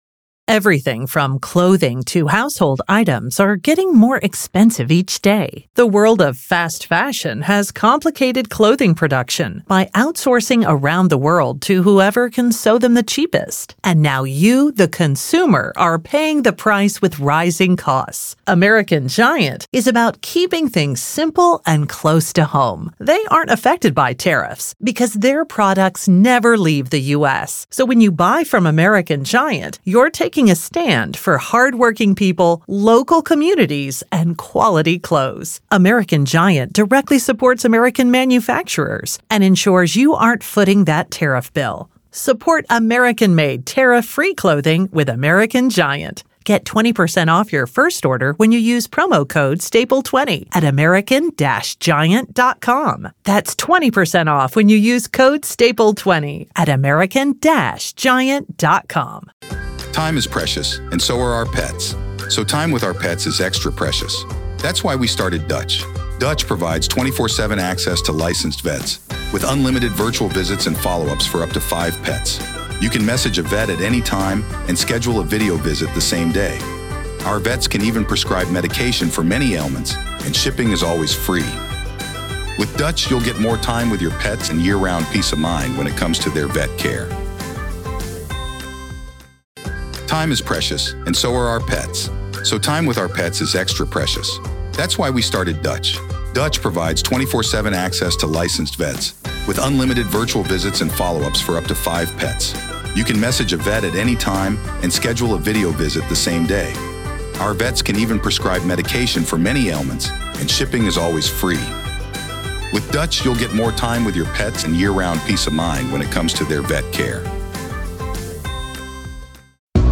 Court Audio-NEVADA v. Robert Telles DAY 4 Part 4